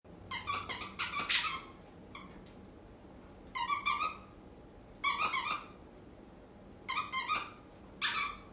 Dog Squeaking Toy Sound Button - Free Download & Play